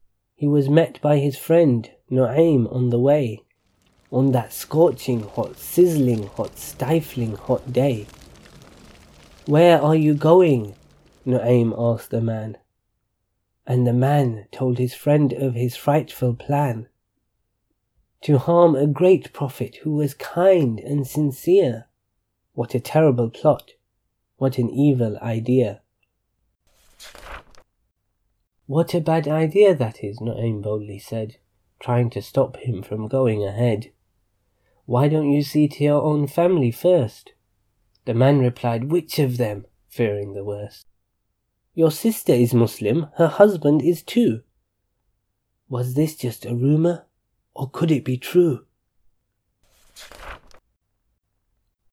Audiobooks are available for each of our storybooks.